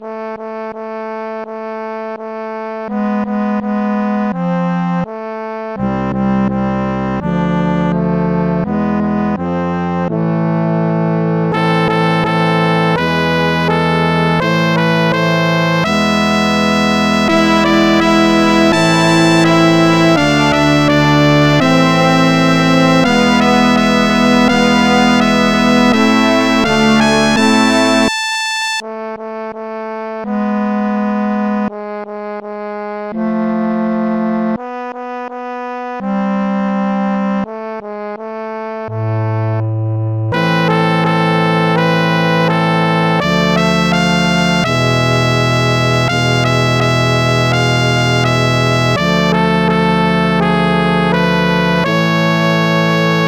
Tracker DSIK DSM-format
Trombone Clarinet 1 Tuba 1 Trumpet 1 Synth Brass 1